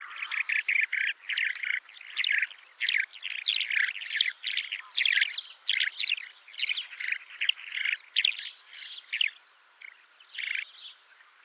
Alauda arvensis - Skylark - Allodola
DATE/TIME: 28/december/2012 (8 a.m.) - IDENTIFICATION AND BEHAVIOUR: A flock of 5 birds fly off from a grazed meadow. They call in flight. - POSITION: Near the estuary of Ombrone river, LAT. N. 42°39'/LONG. E 11°01' - ALTITUDE: 0 m. - VOCALIZATION TYPE: flight calls - SEX/AGE: unknown - COMMENT: Distinctive calls uttered by flying birds. Some are similar to those shown in Recording 3. - MIC: (P)
call5.wav